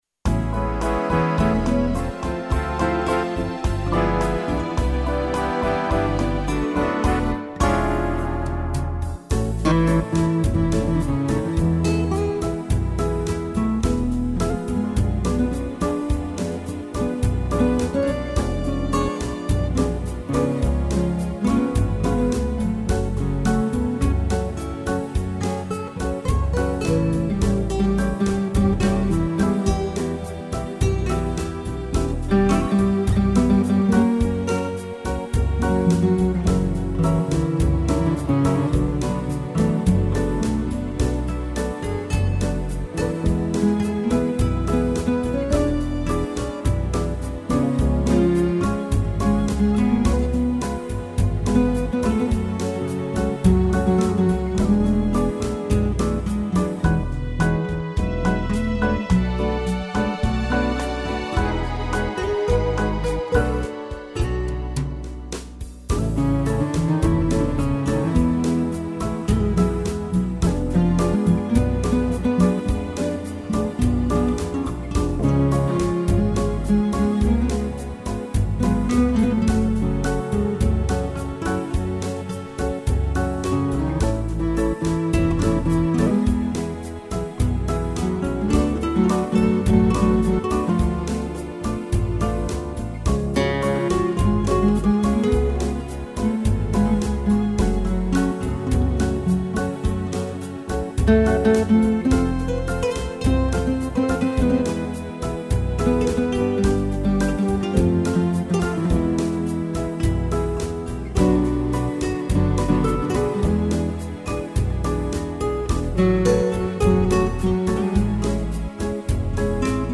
violão